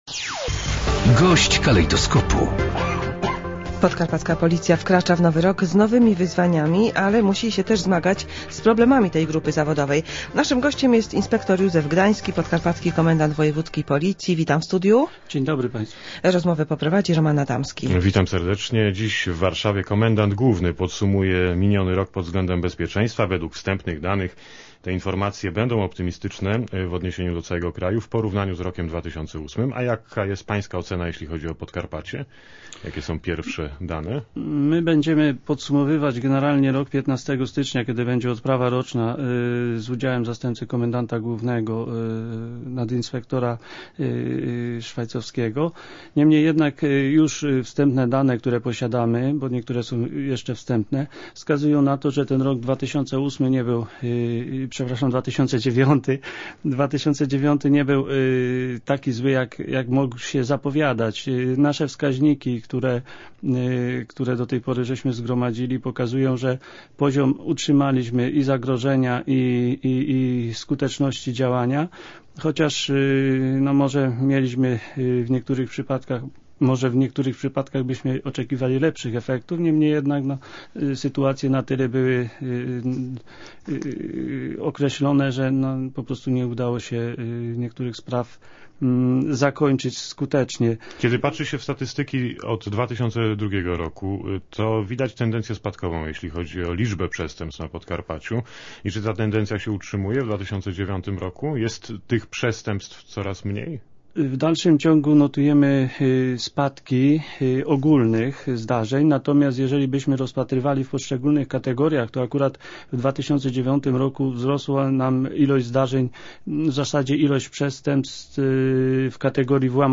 Dziś rano gościem Radia Rzeszów był inspektor Józef Gdański, Podkarpacki Komendant Wojewódzki Policji. Komendant podsumował wstępnie stan bezpieczeństwa w roku 2009 na Podkarpaciu. Mówił o zamierzeniach na rok 2010.